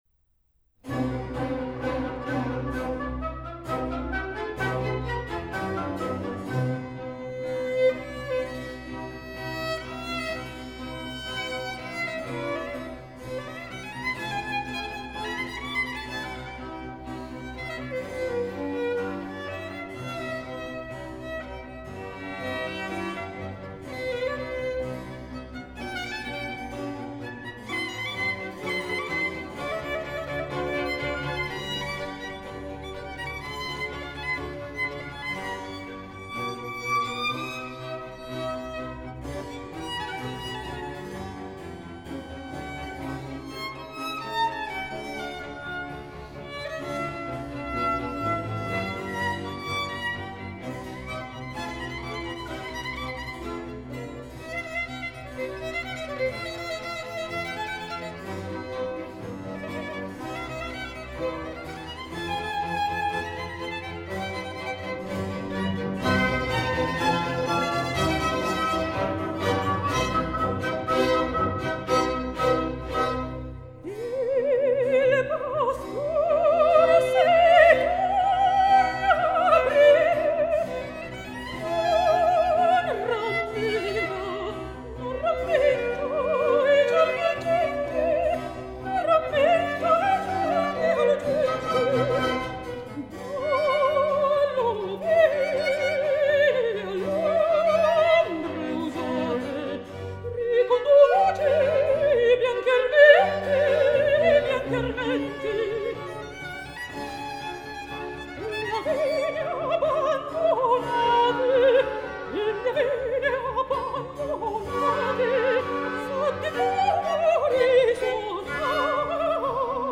amb el preciós acompanyament obligat de violí
mezzosoprano